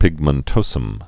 (pĭgmən-tōsəm)